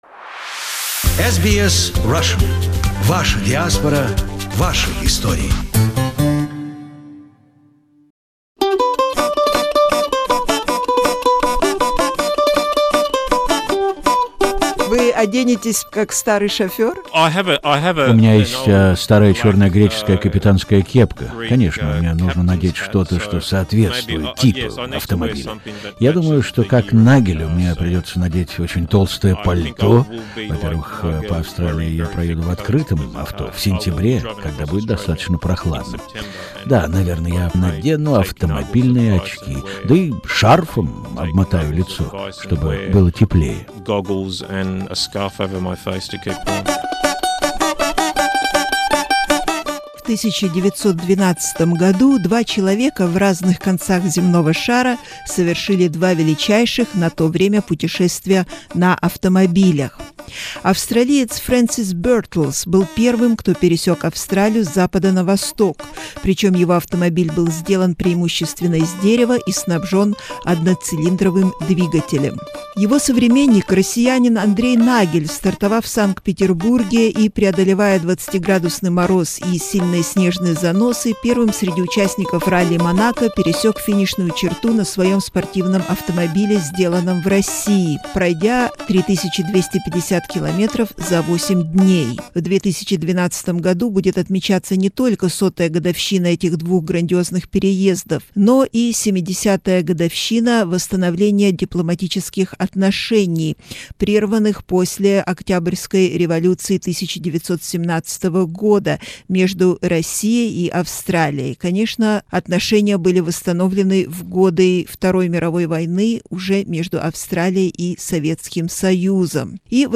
here is the very first interview